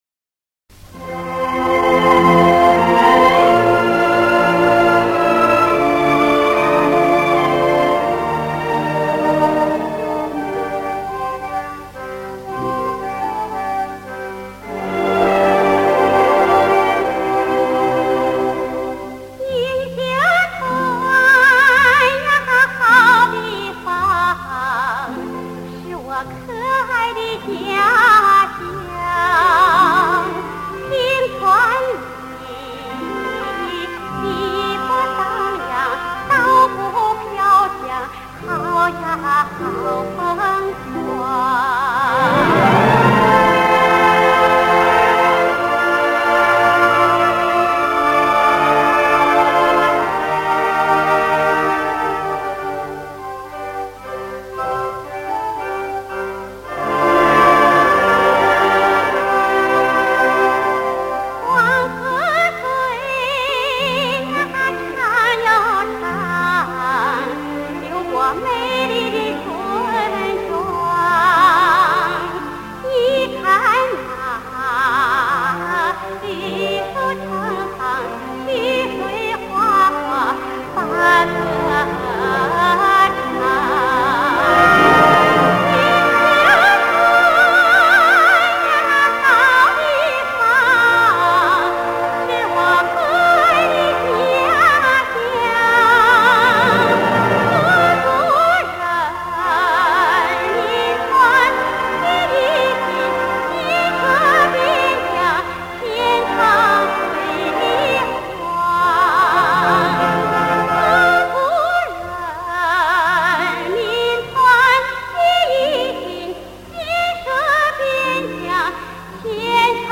是以银川民歌为基调一首独唱歌曲，歌词具有民谣风格，曲调朗朗上口。